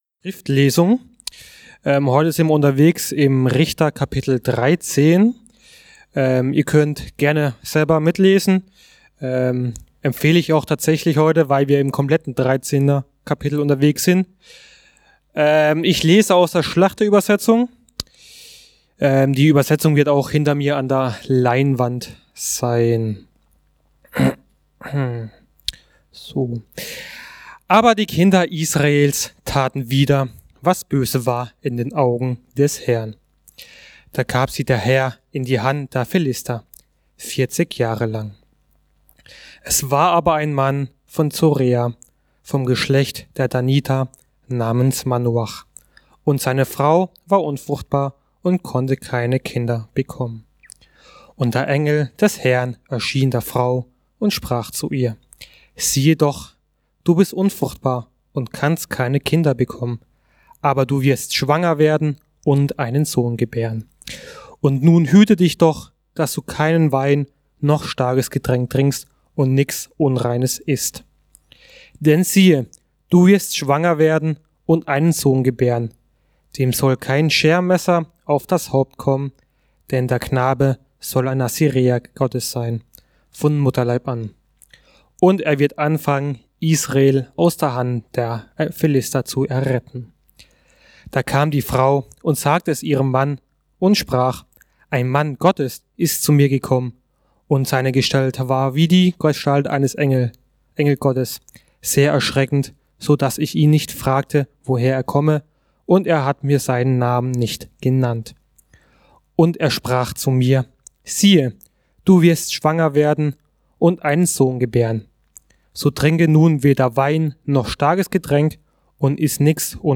Aus Unserer Sackgasse in Gottes Ruhe ~ Mittwochsgottesdienst Podcast